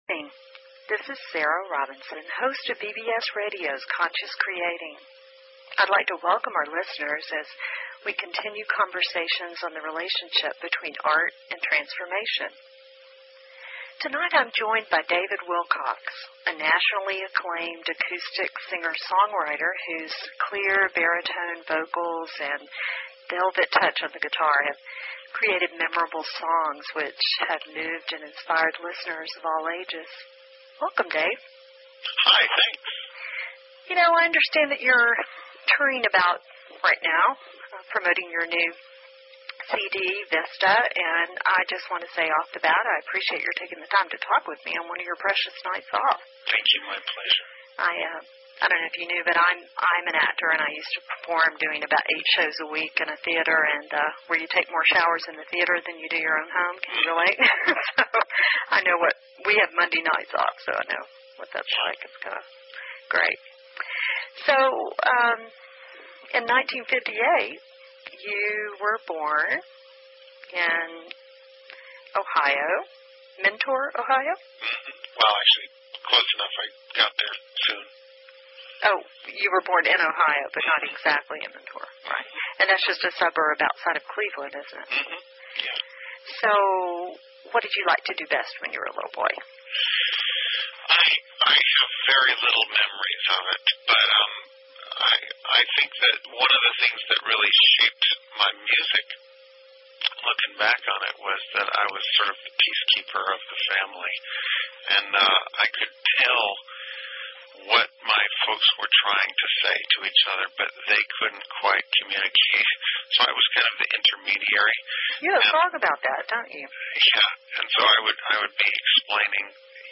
Talk Show Episode, Audio Podcast, Conscious Creating and Courtesy of BBS Radio on , show guests , about , categorized as